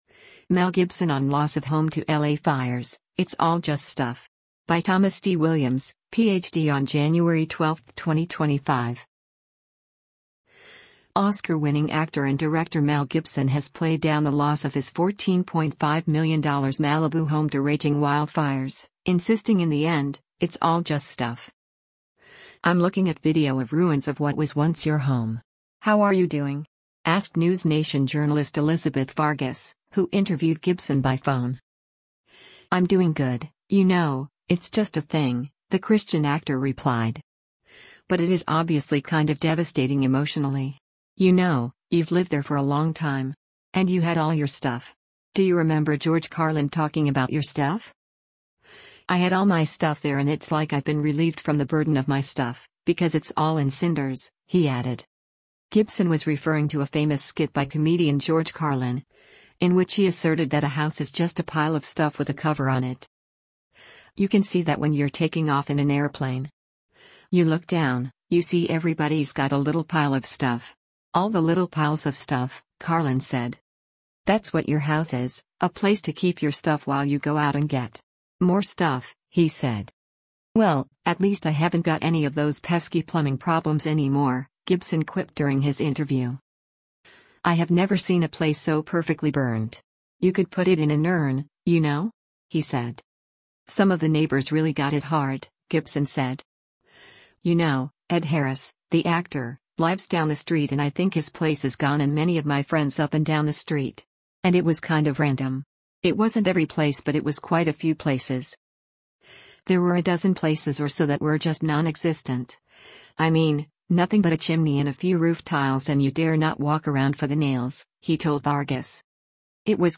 “I’m looking at video of ruins of what was once your home. How are you doing?” asked NewsNation journalist Elizabeth Vargas, who interviewed Gibson by phone.